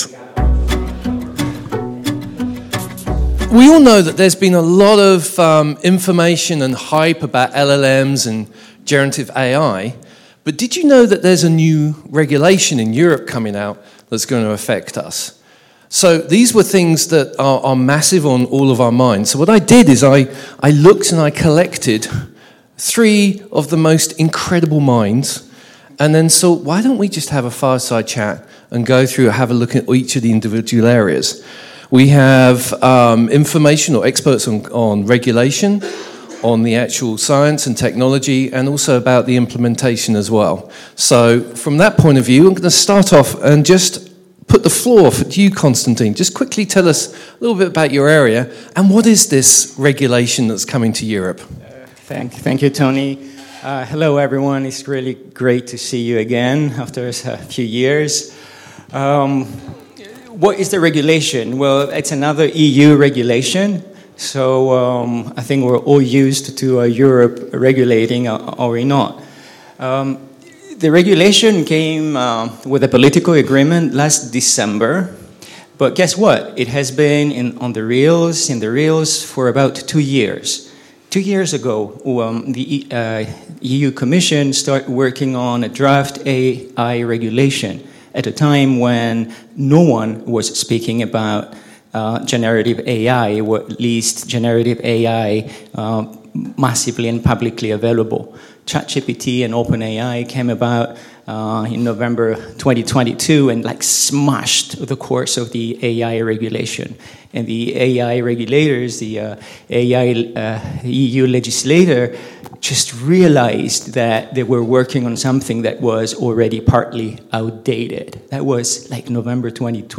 Our panel of experts delves into how AI reshapes industries, ethical challenges, and strategies to mitigate risks. Discover the balance between innovation and integrity in this crucial conversation.